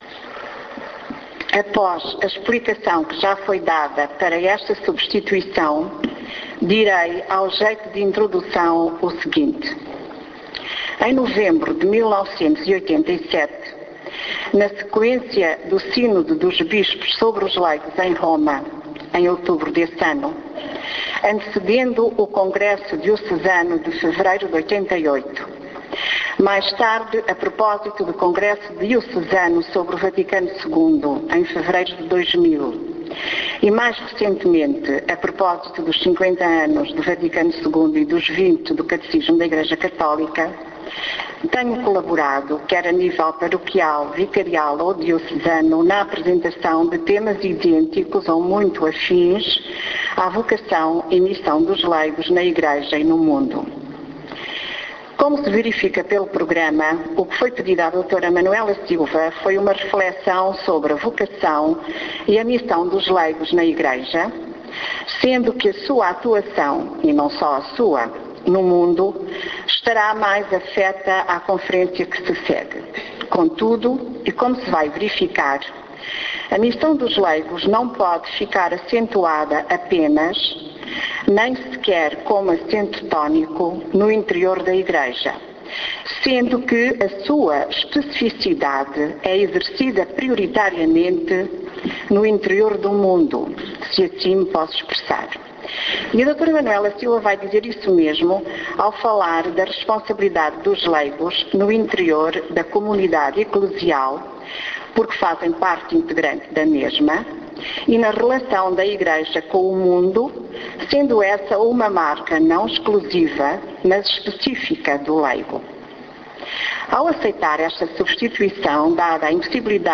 Após a oração da manhã, o simpósio sobre o Concílio Vaticano II que a Igreja Católica do Algarve está a promover em Faro, no salão paroquial de São Luís, hoje com um acrescento de participantes que ascendem a cerca de 300 ontem e hoje, teve início com a apresentação da reflexão de Manuela Silva, que não pôde estar presente devido a um problema grave de saúde.
Reflexao_dra_manuela_silva_simposio_concilio_vaticano.mp3